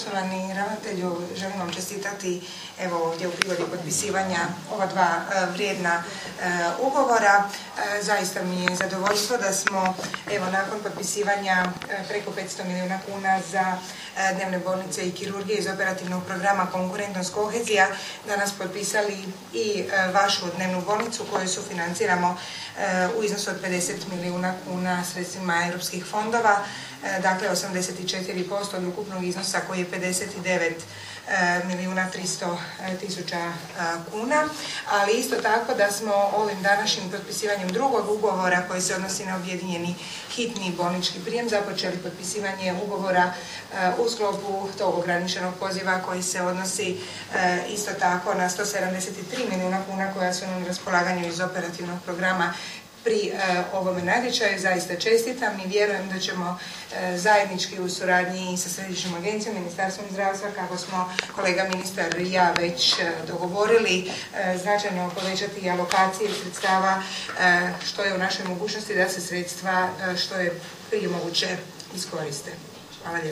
Ministrica regionalnog razvoja i fondova Europske unije Gabrijela Žalac: